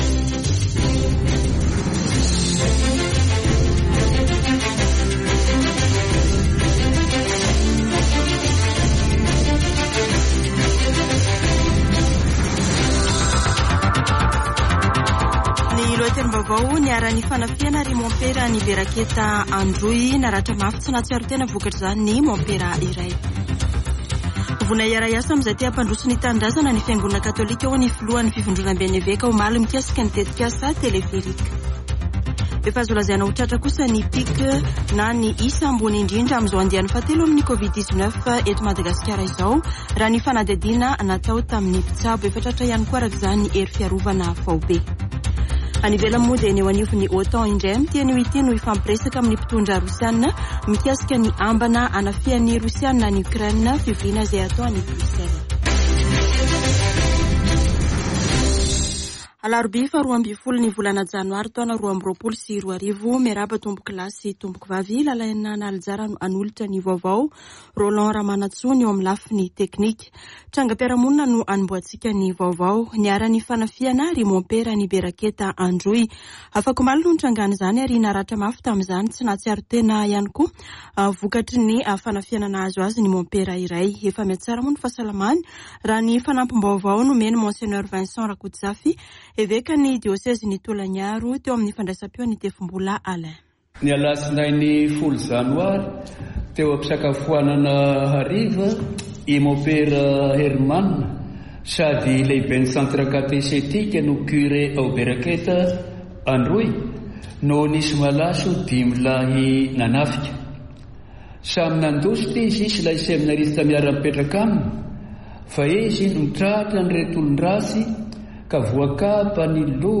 [Vaovao maraina] Alarobia 12 janoary 2022